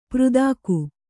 ♪ přdāku